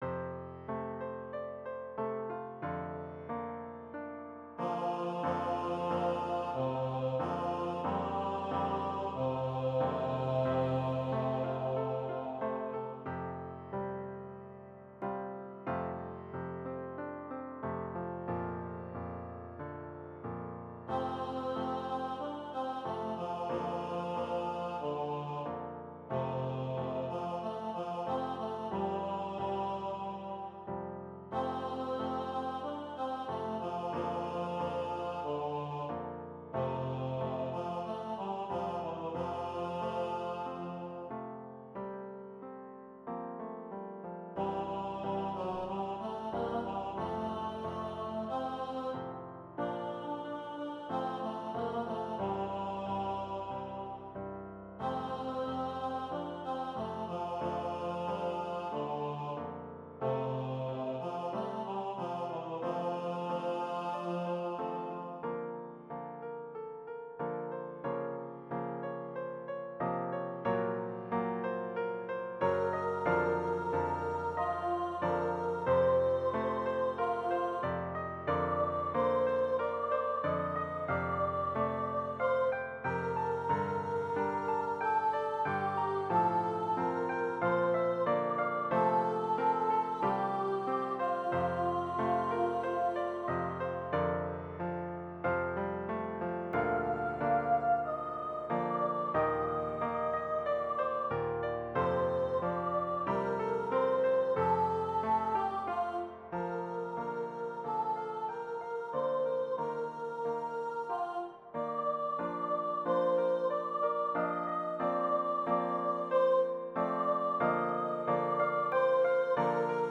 2- Part Choir (or Duet) and Piano